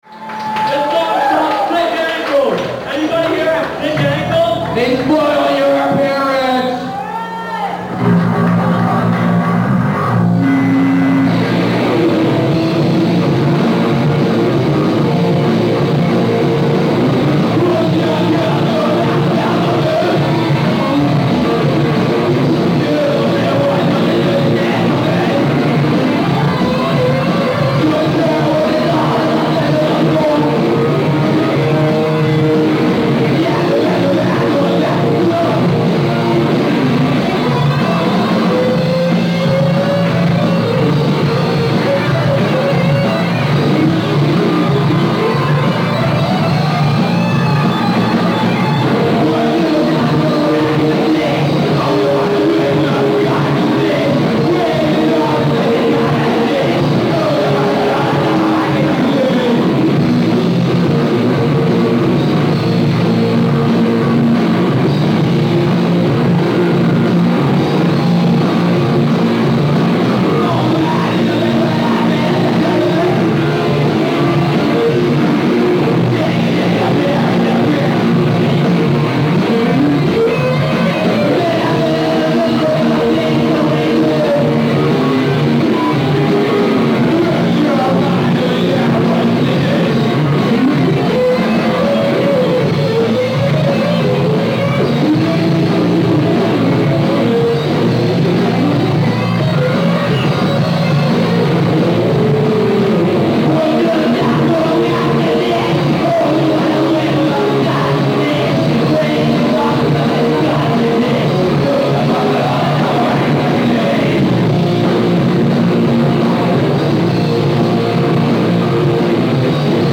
Video from CEC Philadelphia